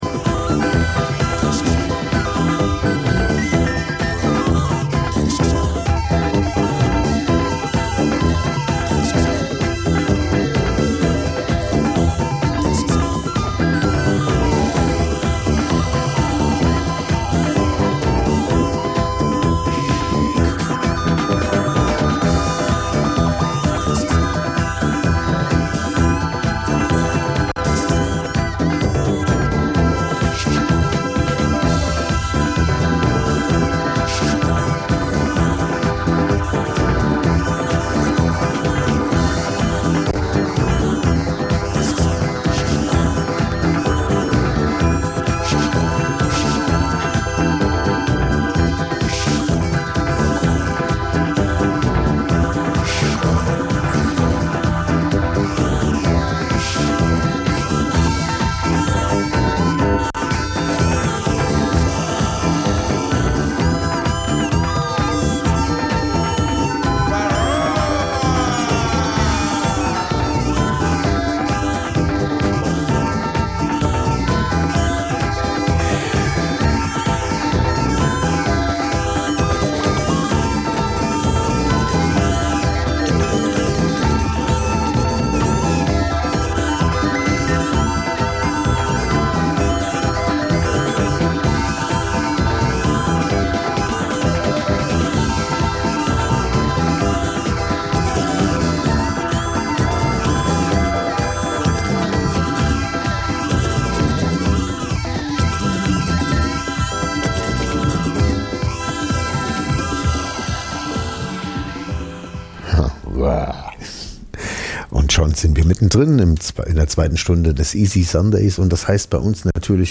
Trotzdem lassen wir es uns nicht nehmen immer wieder sonntags ein paar ganz besondere Perlen unserer Schallplattensammlung einer m�den aber durchaus interessierten H�rer-schaft zu pr�sentieren. Hierbei handelt es sich ausschlie�lich um leicht bek�mmliche Musikst�cke aus dem Be-reich Jazz, Soul, Funk, Soundtracks, Beat, French Pop u.s.w. Ganz nebenbei geben wir dem H�rer Informationen zu den gespielten Musikst�cken und vermitteln ihnen wertvolle Tips zu Bew�ltigung des
Easy Listening Dein Browser kann kein HTML5-Audio.